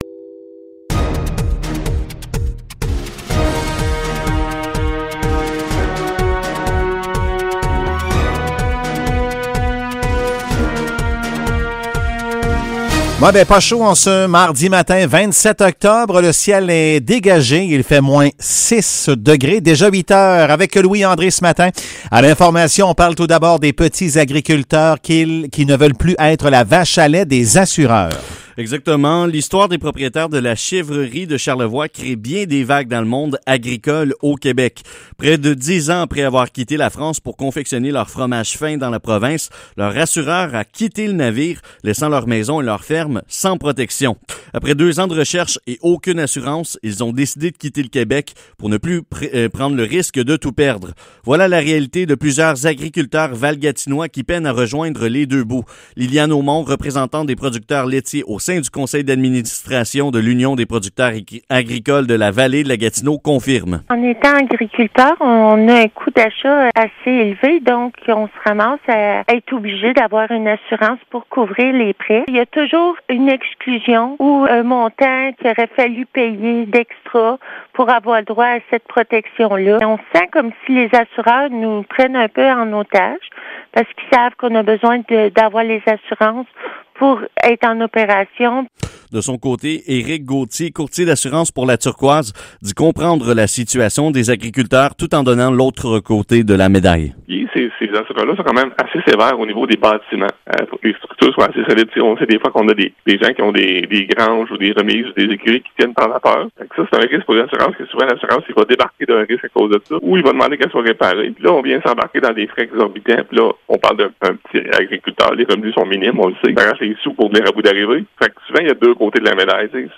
Nouvelles locales - 27 octobre 2020 - 8 h